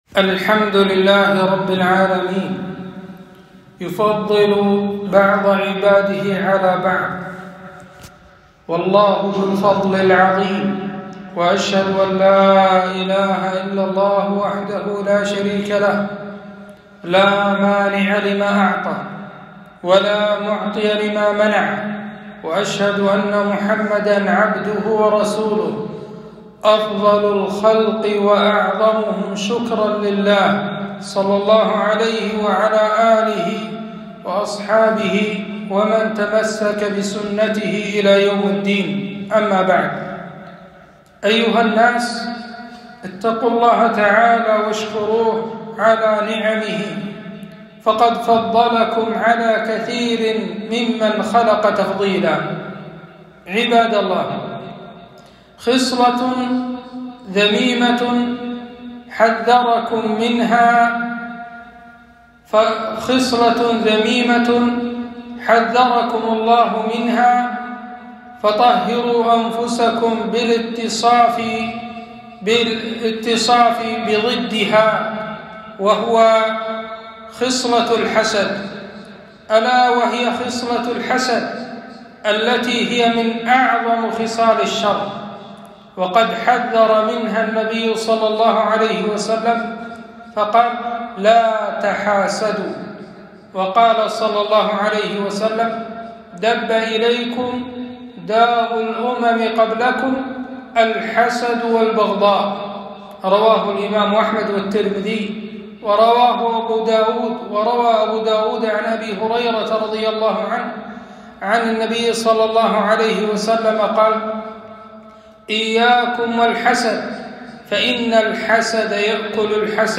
خطبة - ذم خصلة الحسد